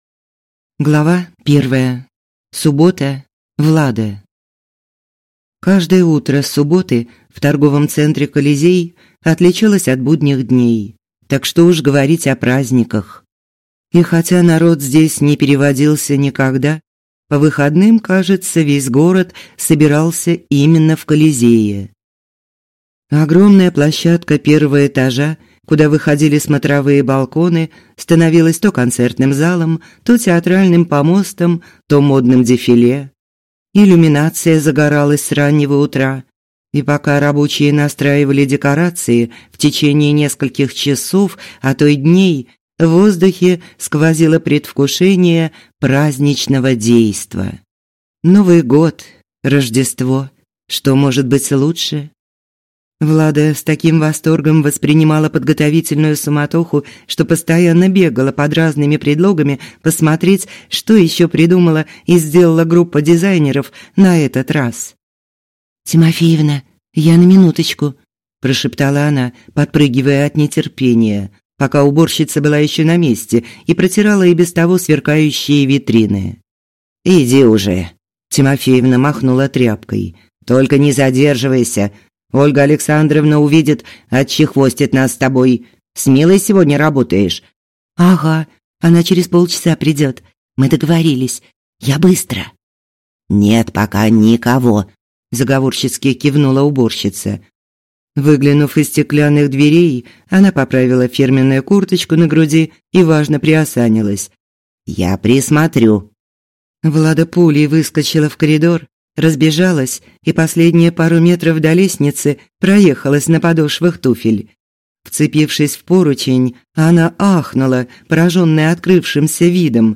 Аудиокнига Та, которой нет | Библиотека аудиокниг